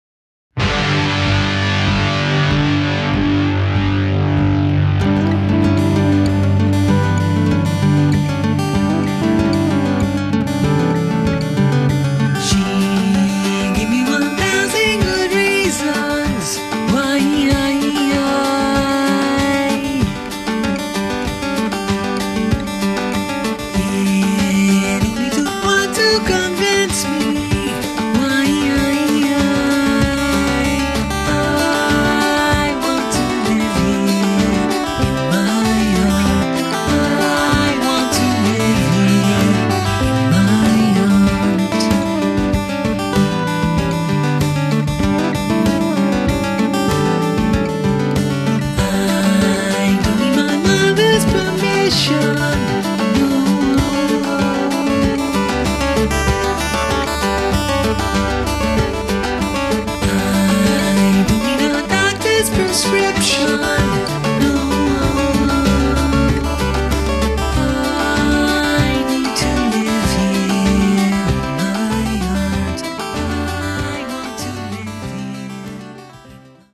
bass, electric guitar, mix
acoustic & electric guitars, drum tracks, vox